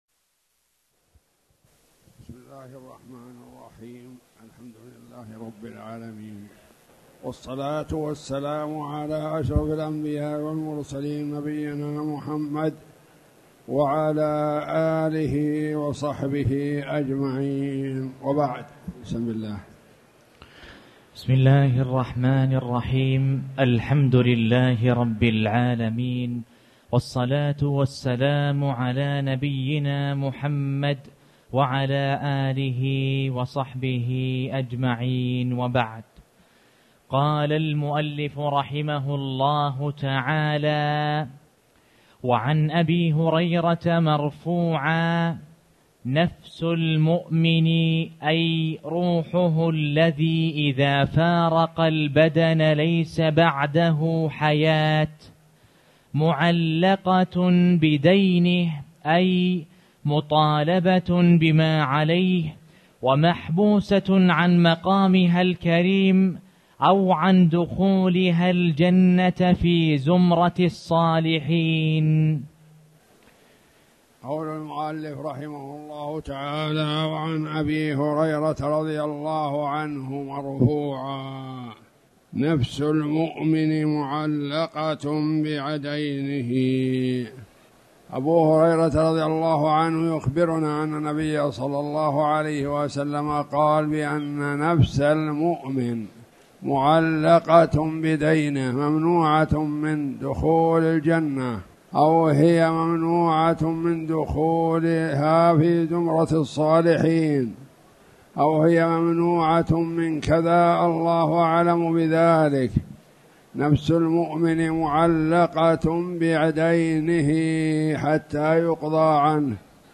تاريخ النشر ٢٠ ذو القعدة ١٤٣٨ هـ المكان: المسجد الحرام الشيخ